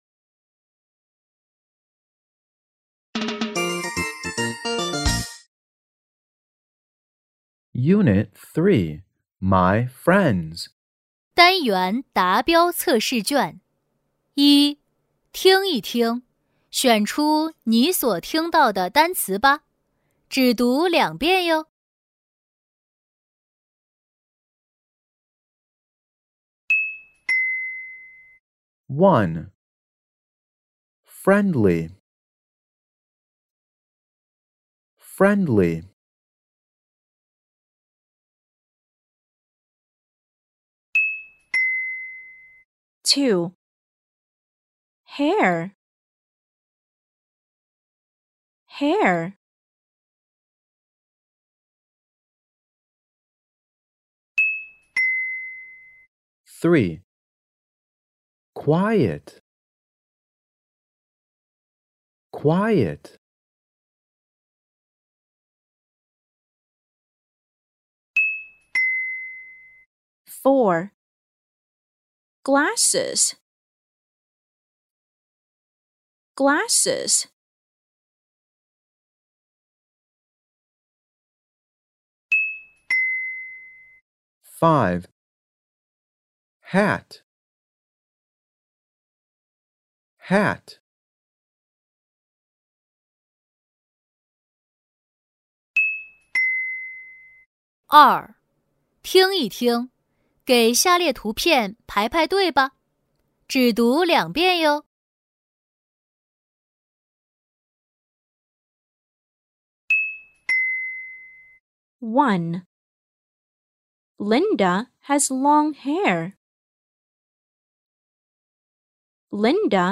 小学单元测试卷-四年级上册-Unit3达标卷-人教版-听力MP3